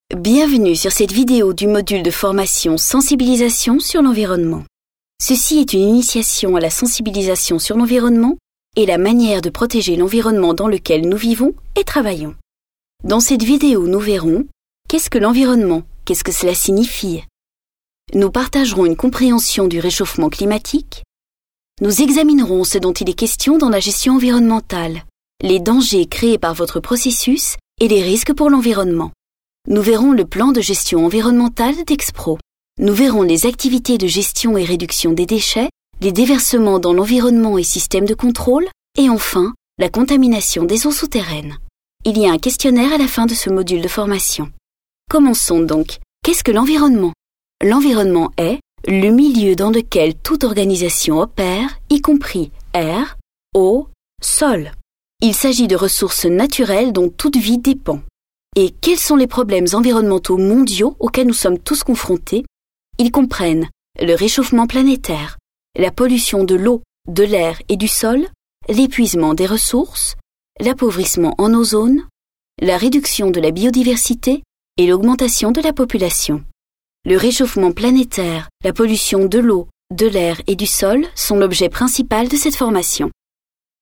Sprechprobe: eLearning (Muttersprache):
I adapt my voice to your needs. sweet, warm, young, serious, right, sensual, funny ...